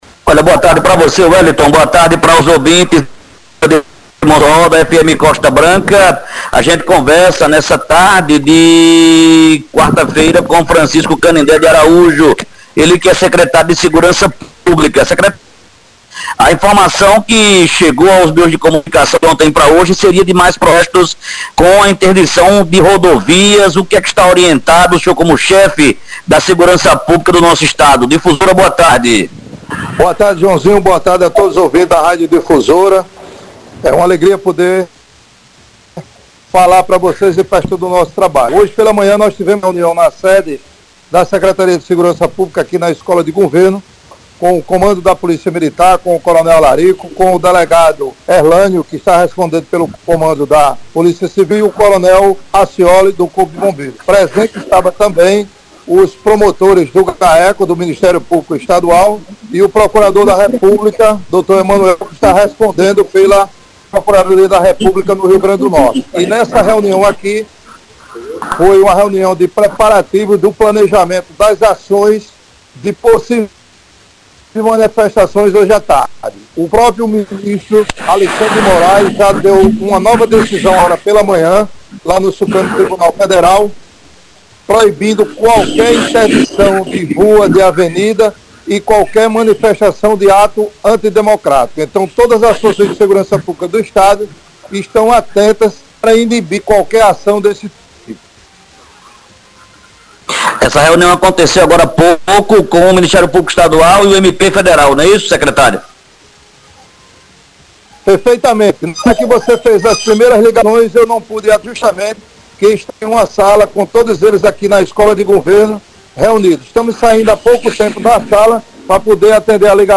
Confira abaixo a fala do coronel Araújo: